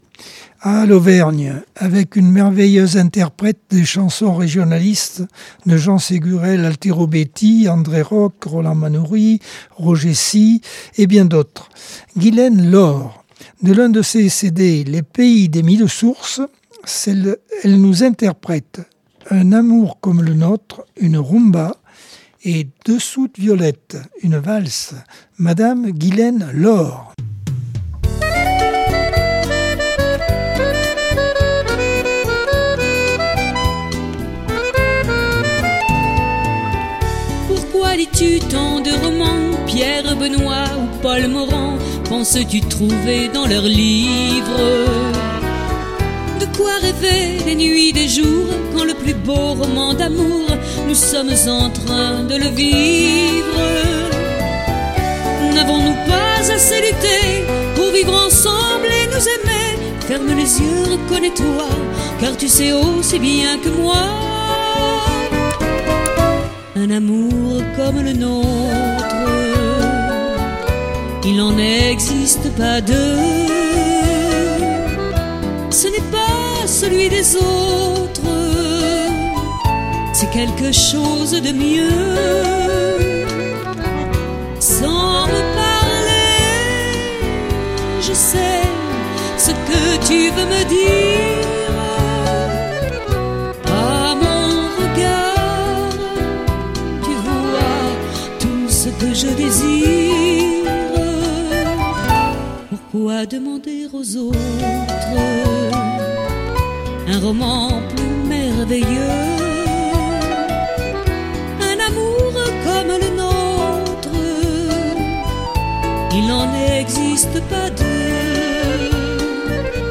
Accordeon 2025 sem 01 bloc 4 - Radio ACX